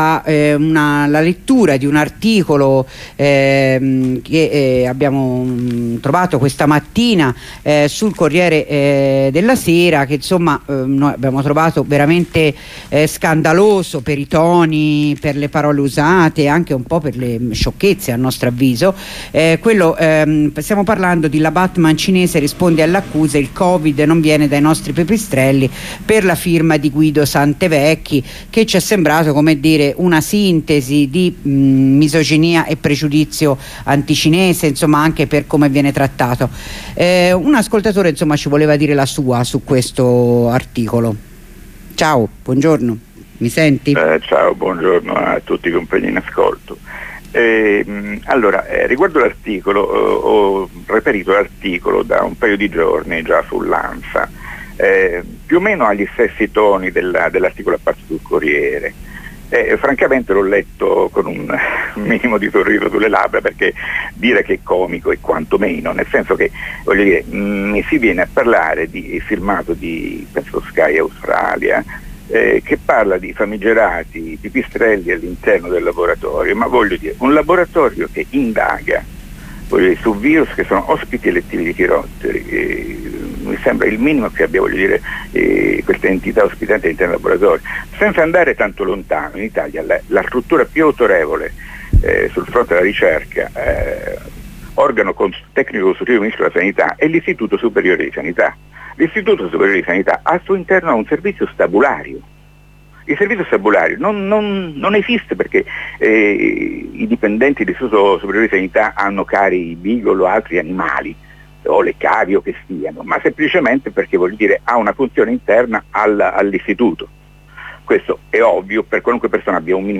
La rassegna stampa di Radio Onda Rossa